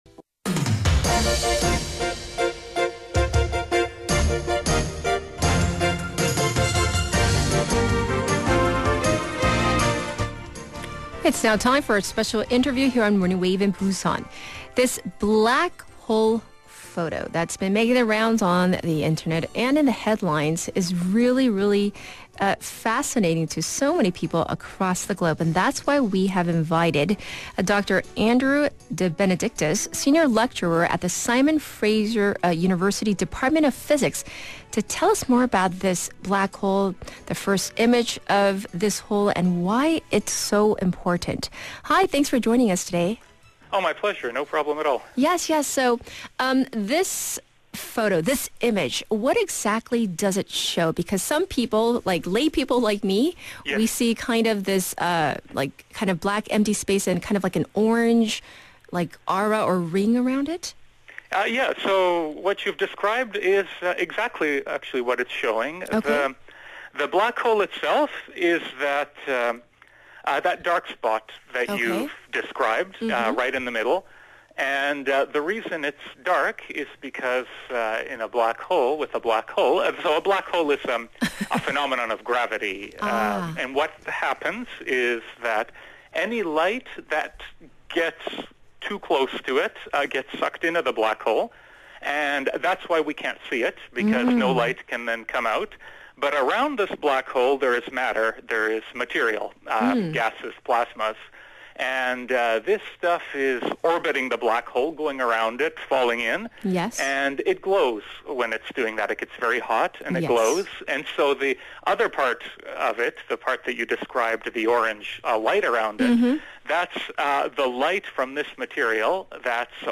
Interview on Morning Wave Busan regarding the first direct imaging of a black hole, April 15th 2019.